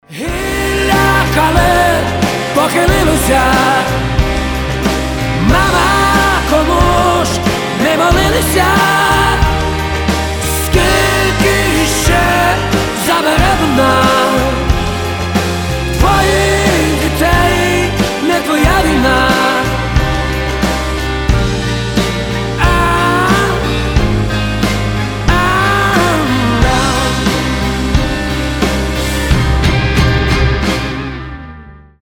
• Качество: 320, Stereo
грустные
печальные